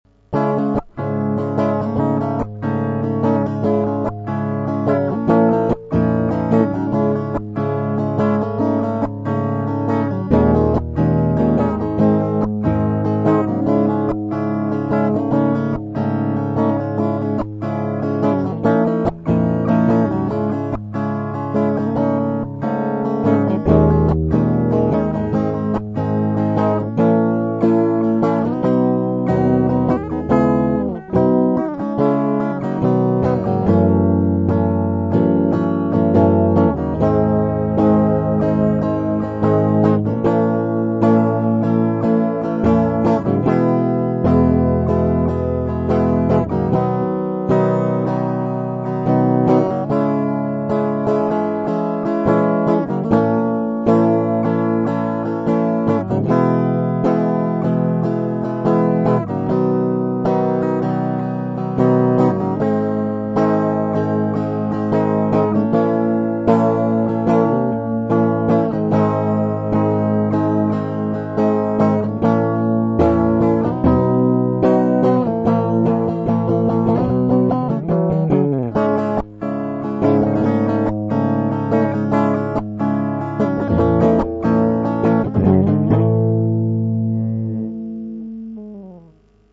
Все струны опущенны на пол-тона.